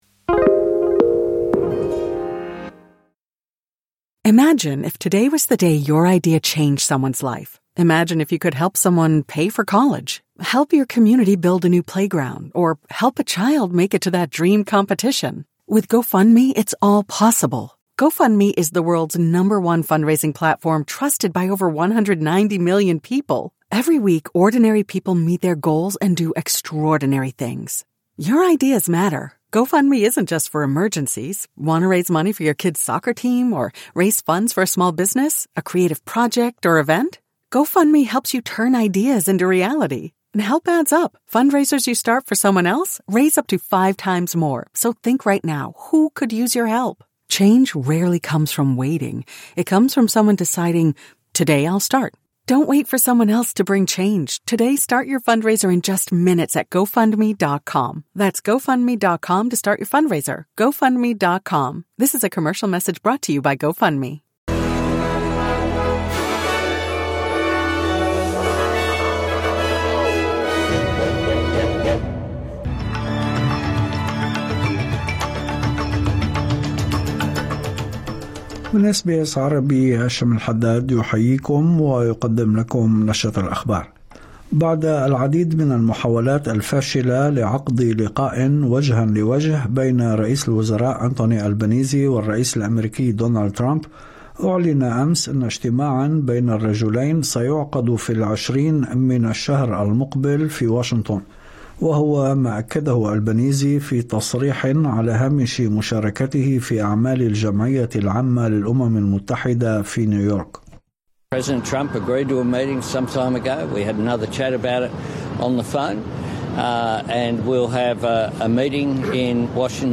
نشرة أخبار الظهيرة 24/09/2025